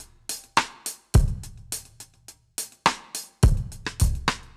Index of /musicradar/dub-drums-samples/105bpm
Db_DrumsA_Dry_105_01.wav